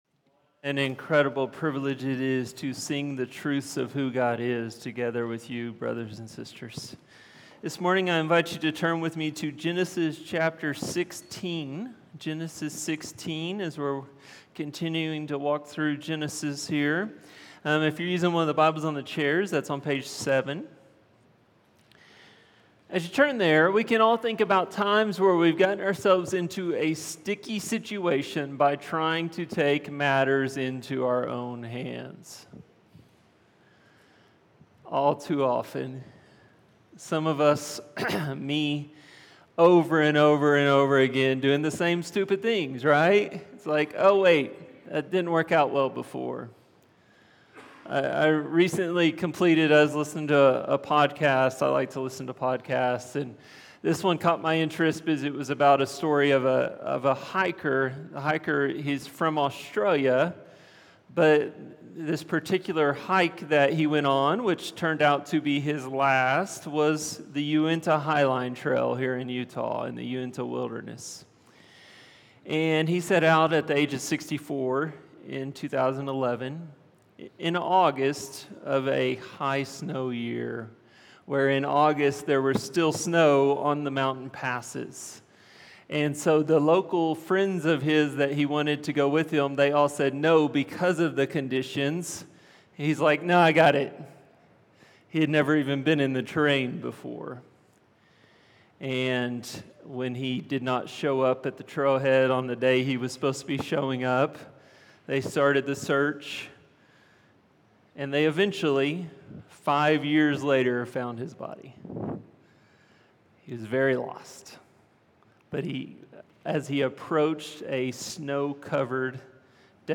A message from the series "Genesis 12-25."